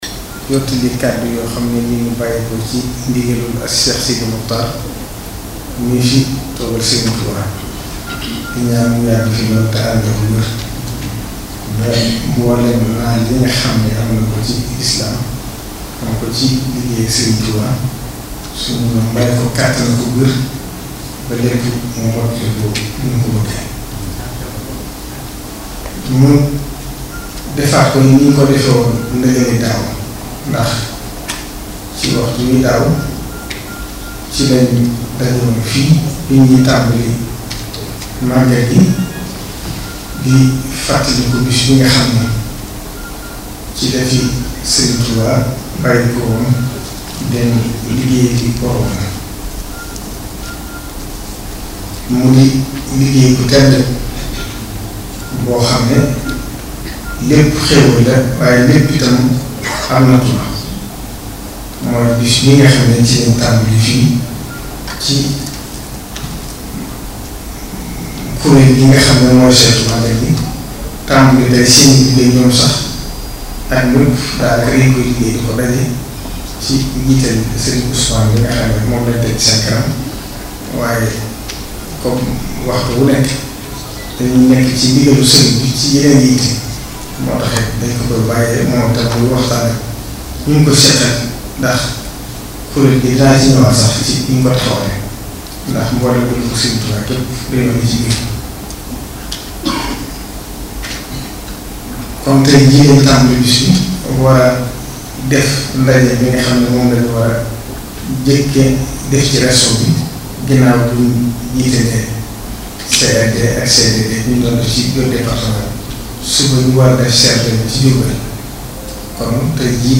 AUDIO ( Intégralité du point de presse )